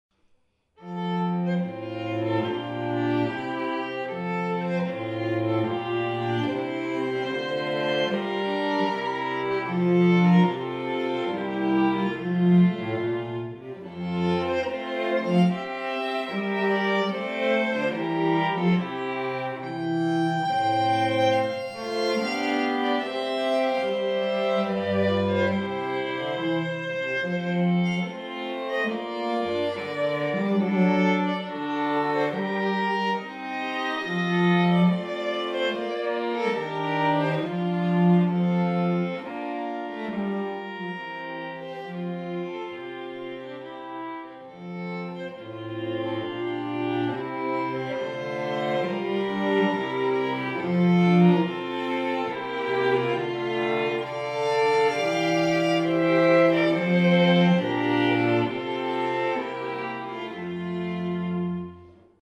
repertoire – Hire String Quartet Brisbane Queensland